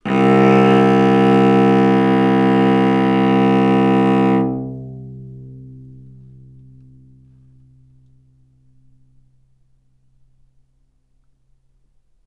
vc-C2-ff.AIF